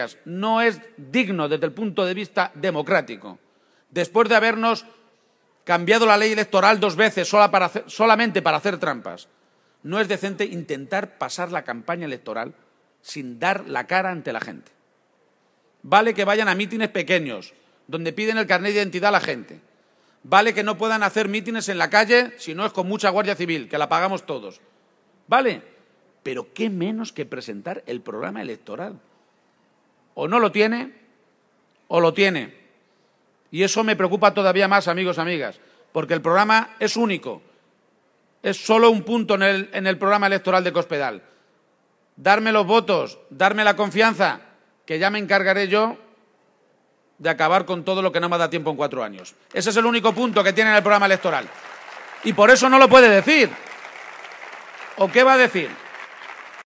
El candidato socialista a la Presidencia de Castilla-La Mancha protagonizo el mitin de fin de campaña en Albacete y anuncio que promoverá un código ético público que obligue a los partidos a presentar programa